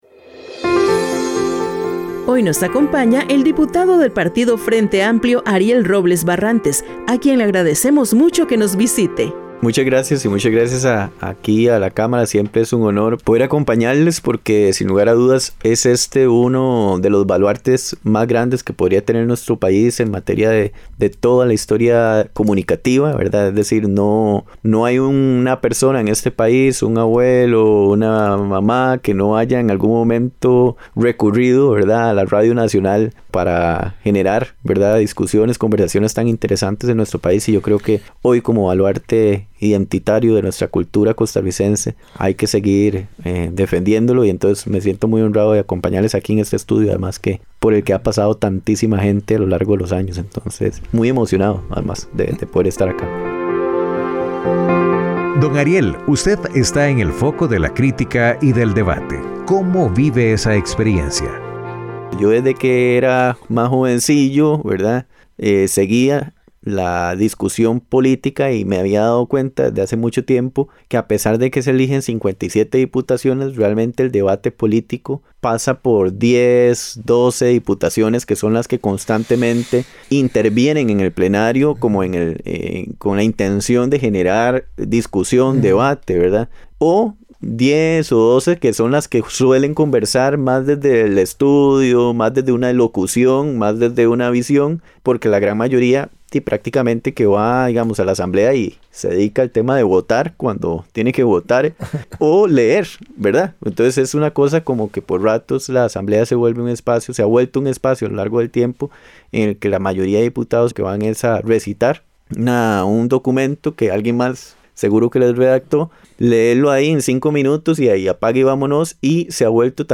Entrevista al diputado Ariel Robles